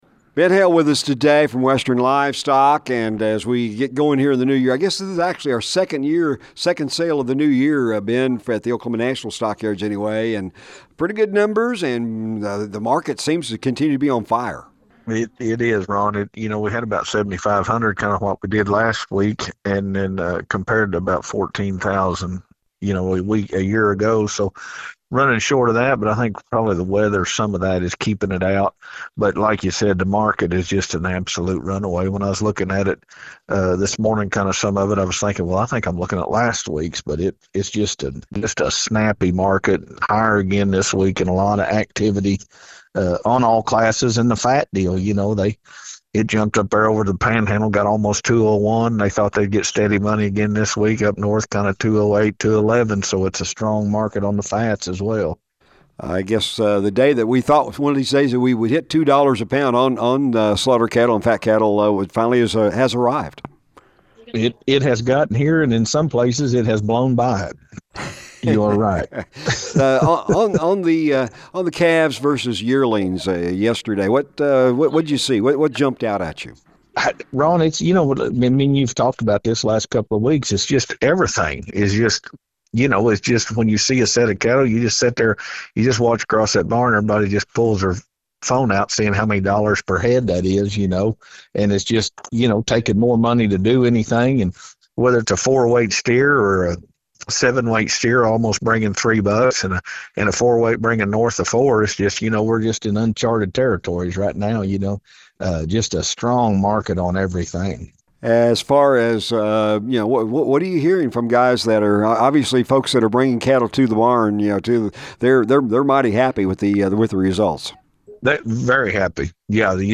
market commentary